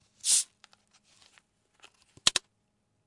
桅杆摇动瓶
描述：摇一瓶苏打水
Tag: 液体 音乐学院混凝土 瓶摇晃